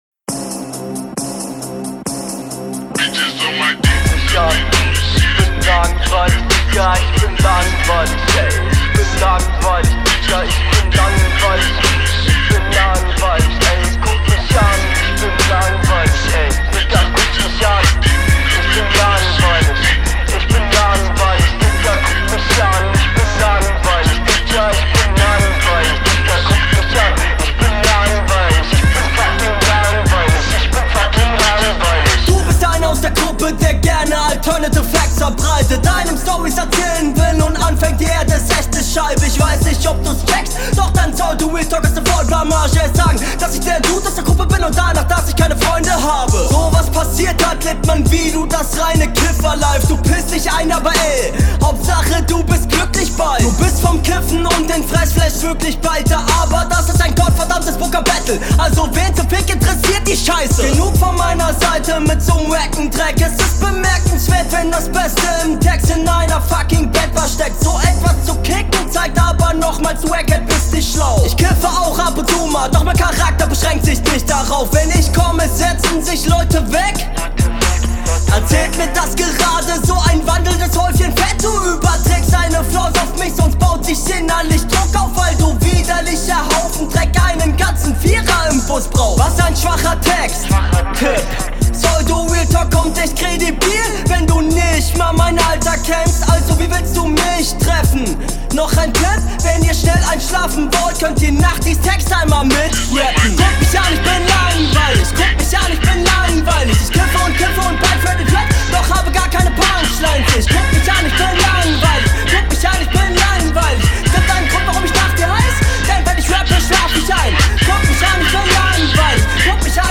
Teilweise verspielt, dein Stimmeinsatz gefällt mir …